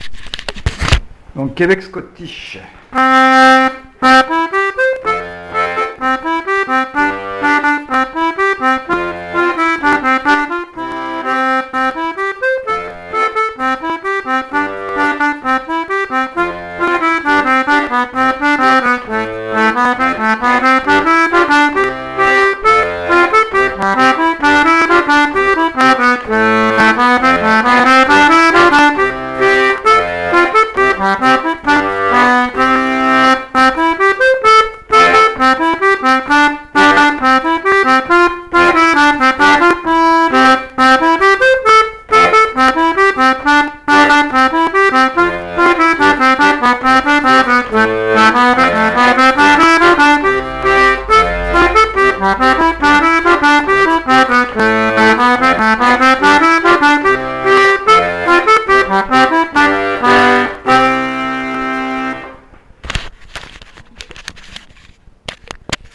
l'atelier d'accordéon diatonique
enregistrement lentement